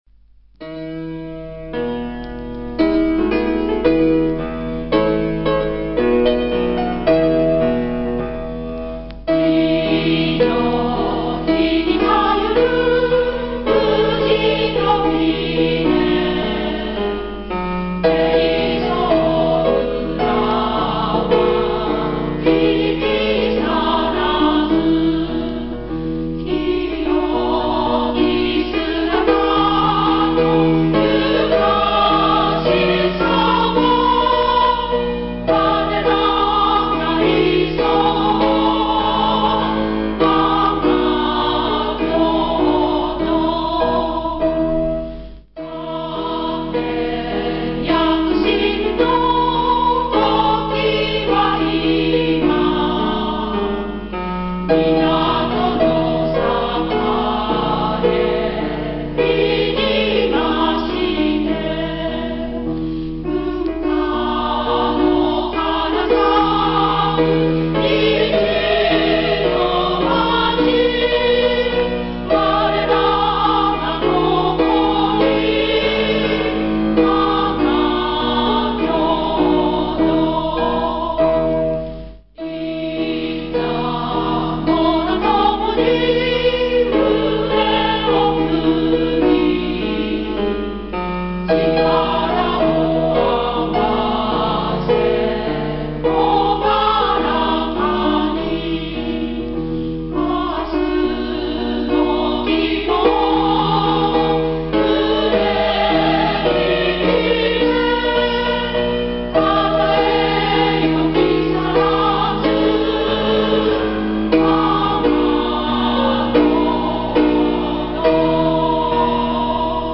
木更津市民歌（女声アルトパート） （mp3） (音声ファイル: 4.8MB)